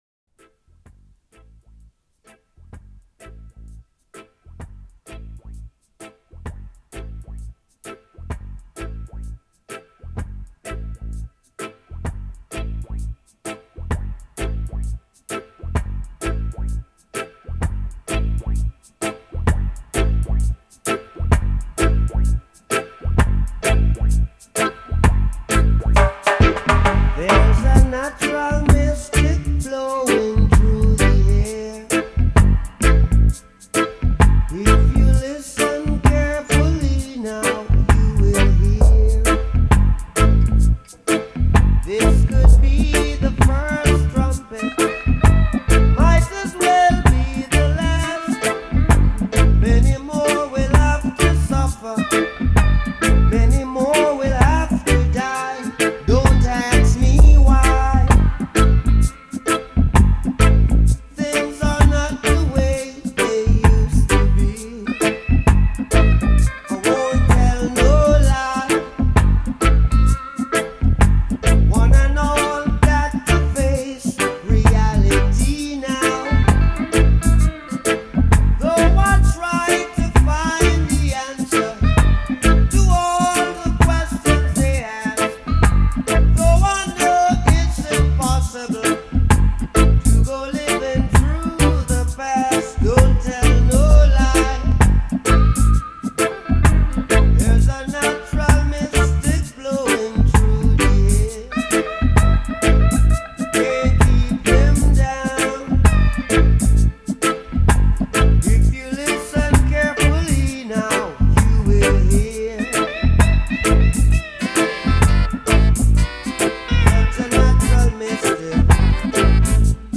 Recorded in London